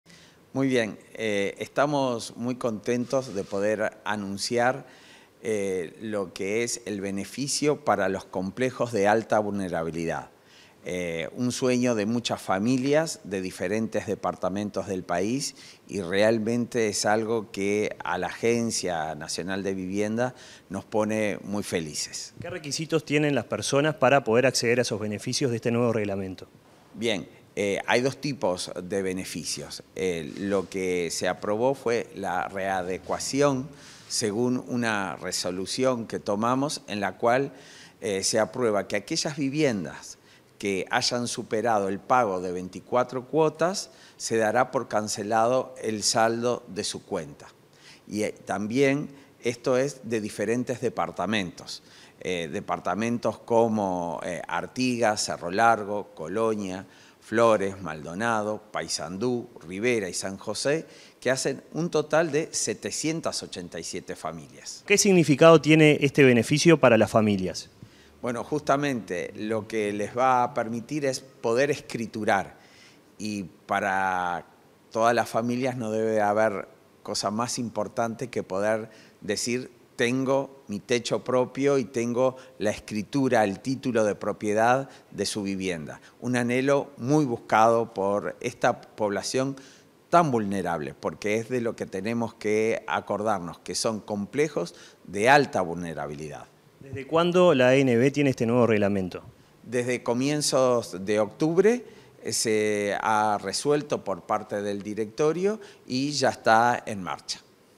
Entrevista al vicepresidente de la ANV, Dario Castiglioni